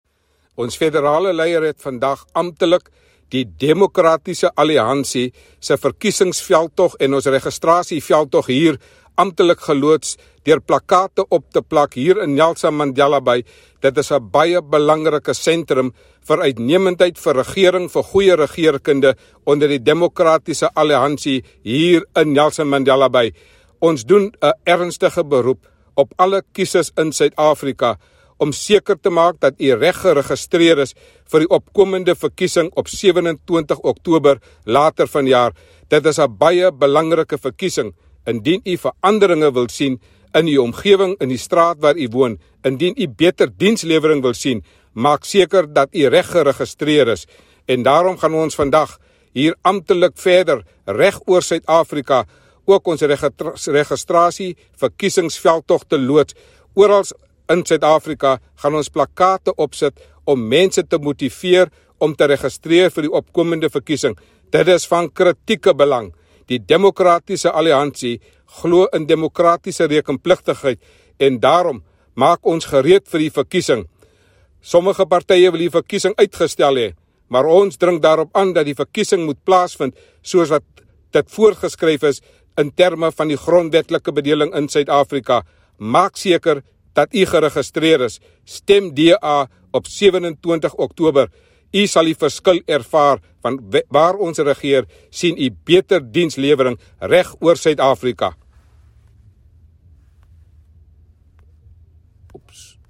English soundbite;